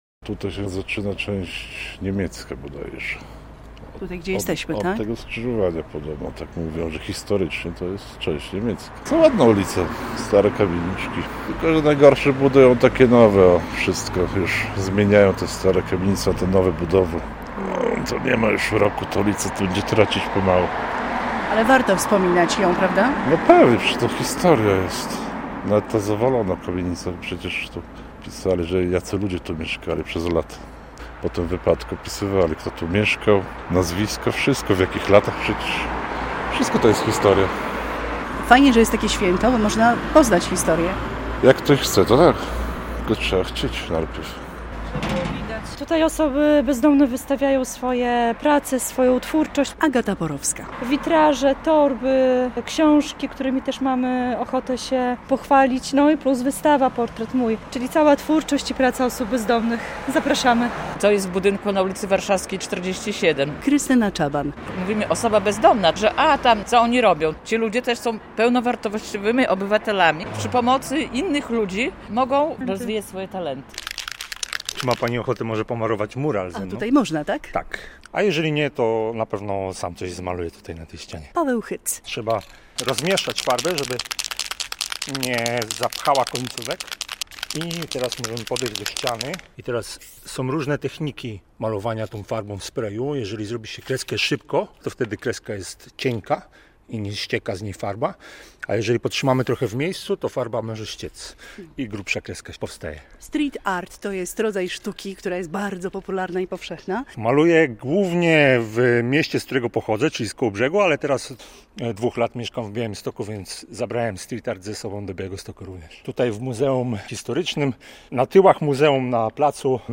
Święto ulicy Warszawskiej w Białymstoku - relacja